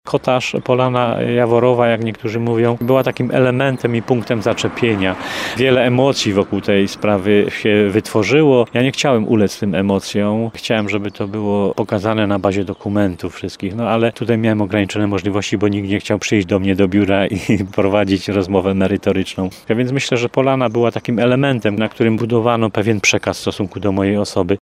Myślę, że polana była takim elementem, na którym budowano pewien przekaz w stosunku do mojej osoby – mówi z prawie niezauważalna nutką żalu w głosie Jerzy Pilch.